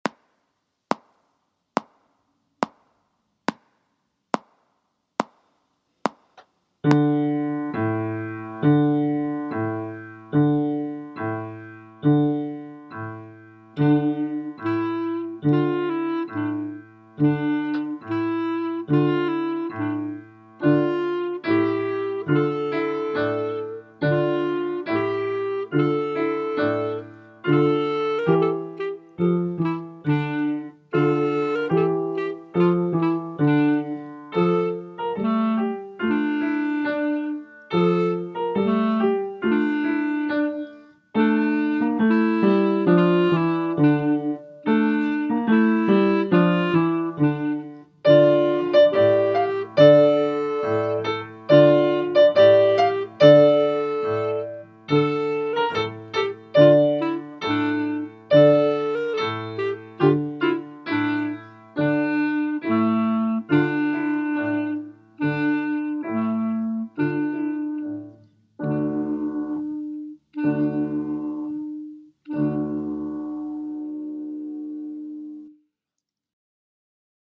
An episode by Simon Balle Music